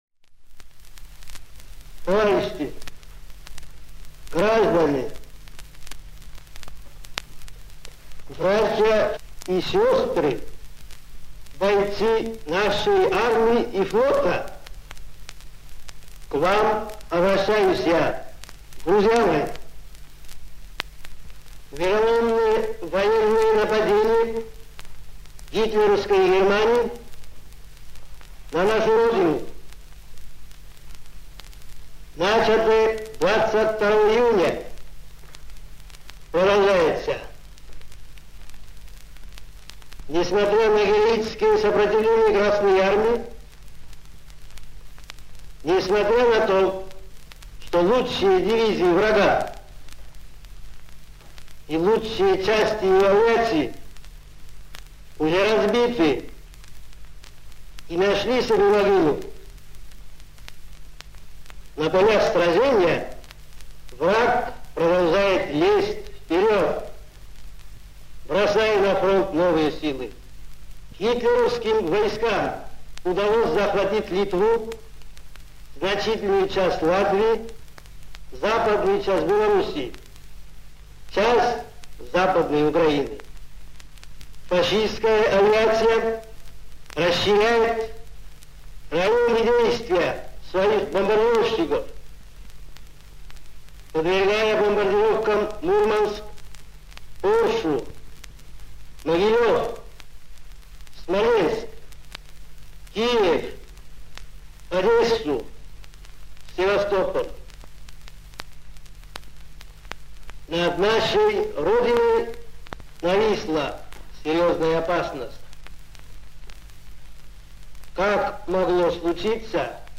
Выступление по радио Председателя Государственного Комитета Обороны И. В. Сталина 3 июля 1941 года. Запись 1941 г.
Stalin_rech_3jul.ogg